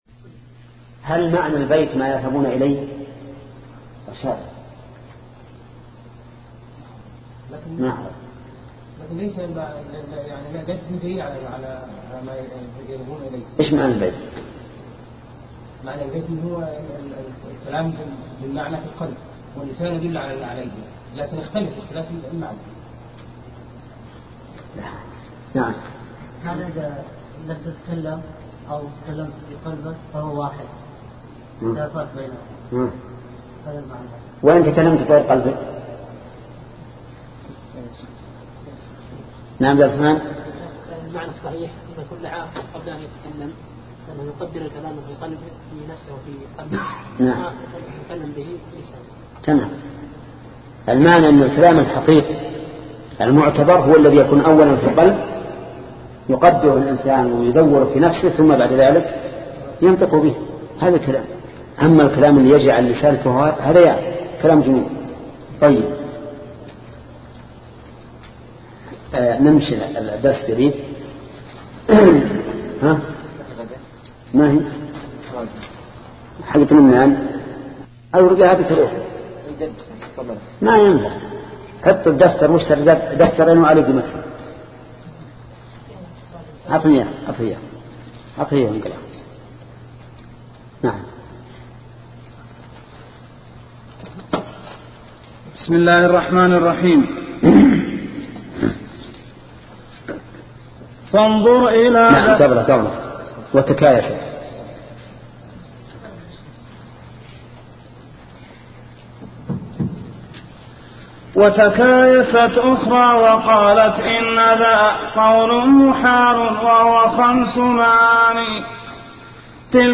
شبكة المعرفة الإسلامية | الدروس | التعليق على القصيدة النونية 8 |محمد بن صالح العثيمين